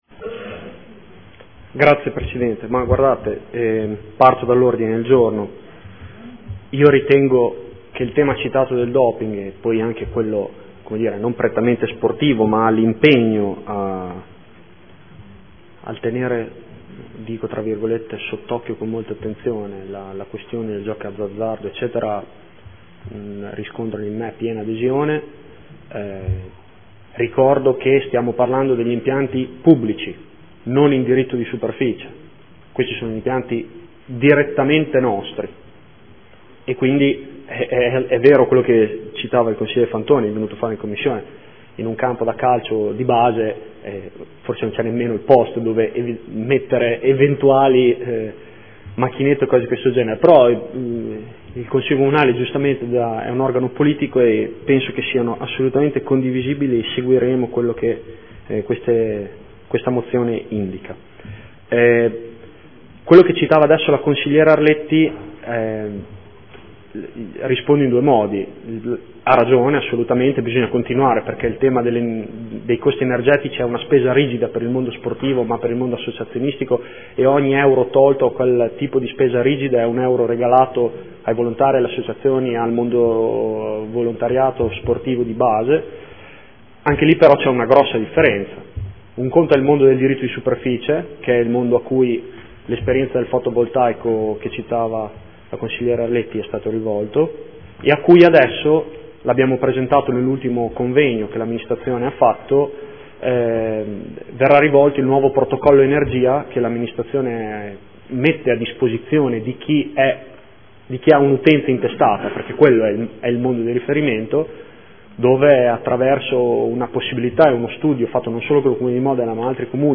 Giulio Guerzoni — Sito Audio Consiglio Comunale
Seduta del 09/04/2015 Replica. Linee di indirizzo per l’affidamento e la gestione degli impianti sportivi di proprietà del Comune di Modena o in sua disponibilità